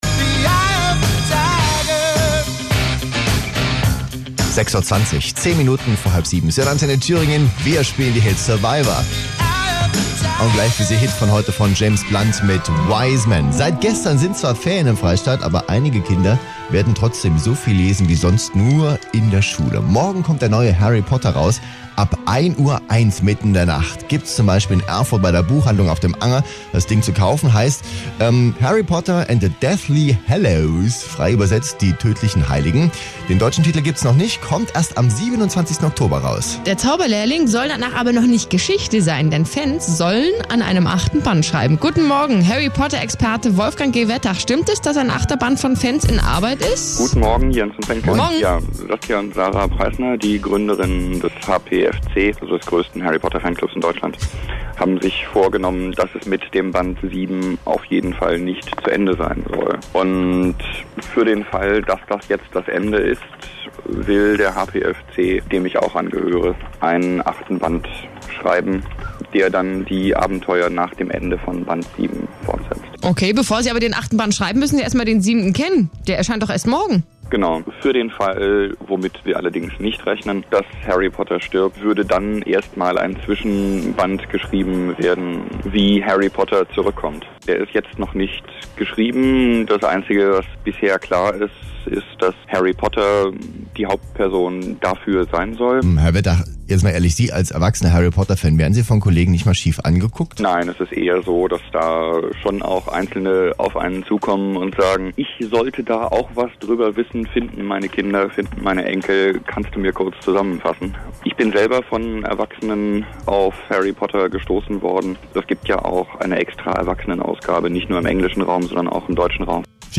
Das gekürzte Interview beinhaltete keine Hinweise auf saveharrypotter oder auf J K Rowlings "Sag niemals nie", aber gibt wieder was über den Fan-Band 8 heute schon zu sagen ist, den der HPFC schreiben will.